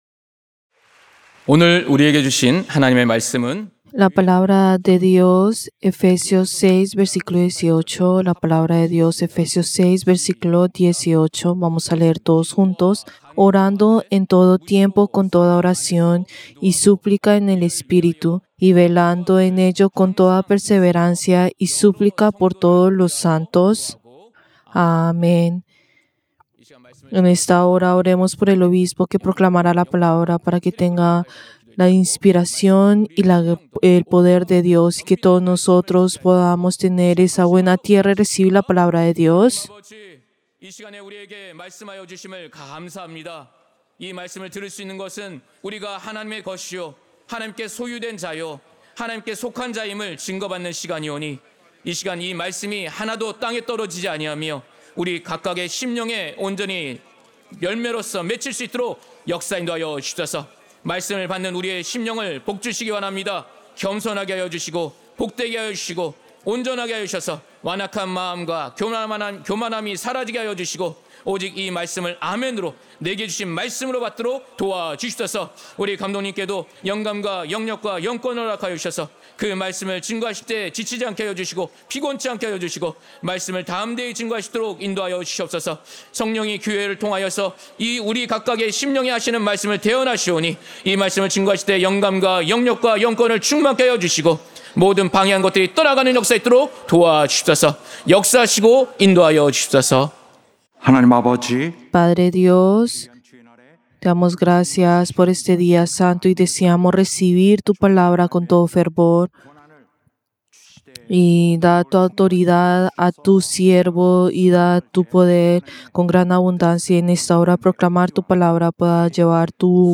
Servicio del Día del Señor del 10 de agosto del 2025